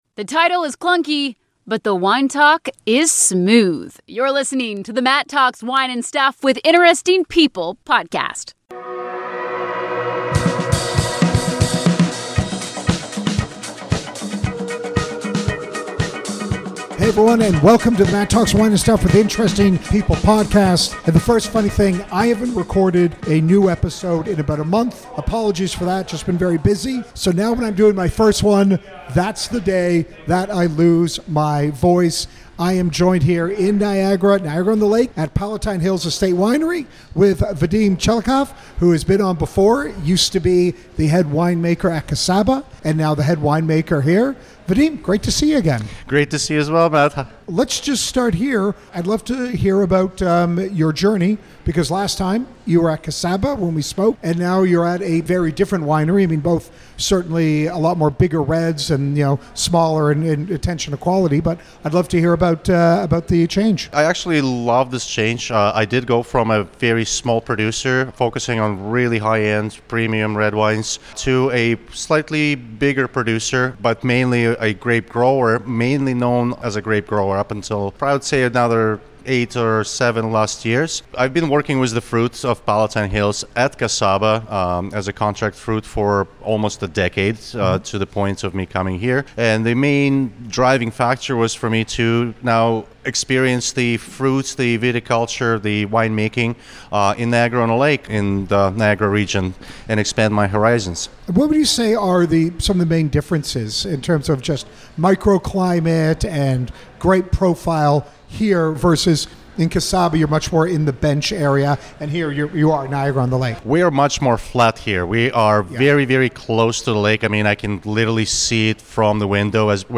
(Apologies for my horrible voice!!!)